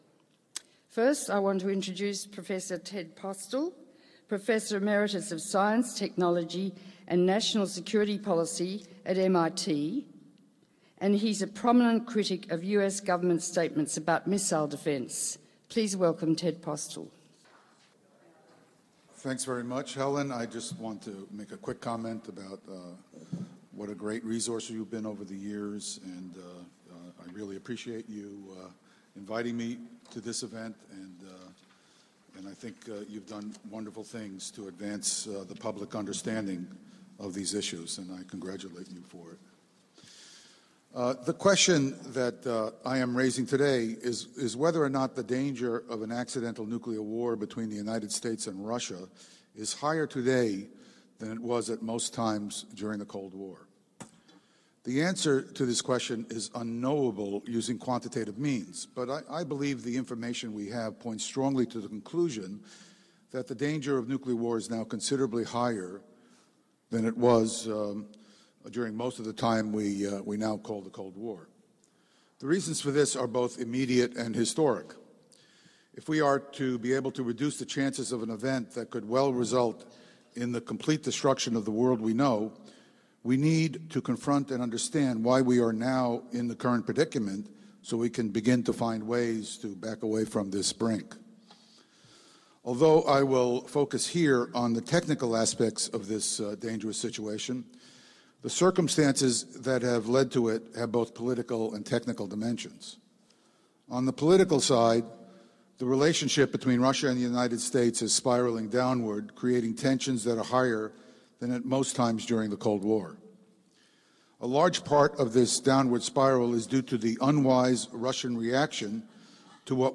This presentation of Dr. Theodore Postol was recorded on 28 February 2015 at The Dynamics of Possible Nuclear Extinction Symposium , presented by The Helen Caldicott Fondation , at The New York Academy of Medicine .